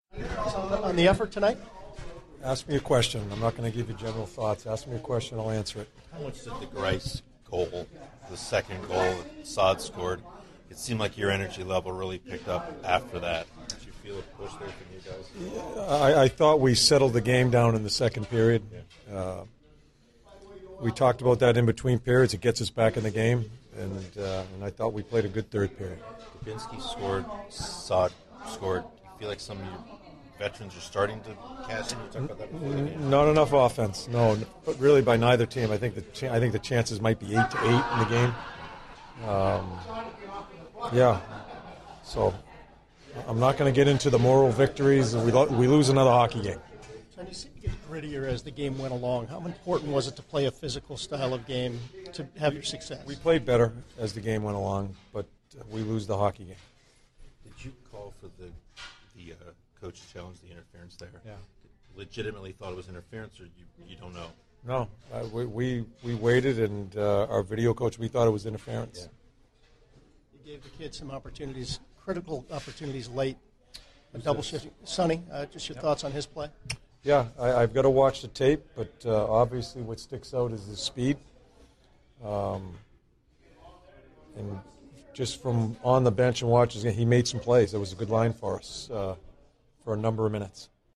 CBJ Interviews / John Tortorella Post-Game 03/31/16
John Tortorella Post-Game 03/31/16